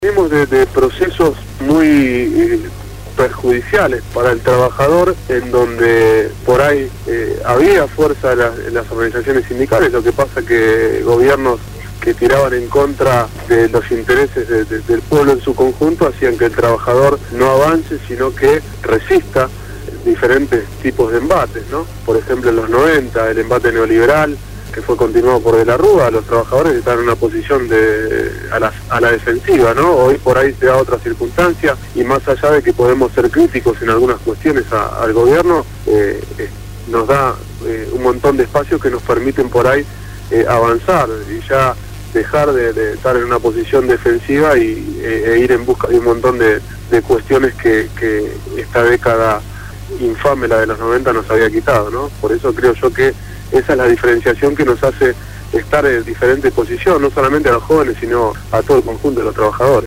Facundo Moyano, Secretario General del Sindicato Unico de Trabajadores de Peajes y Afines (SUTPA) fue entrevistado en «Voces Portuarias» (Martes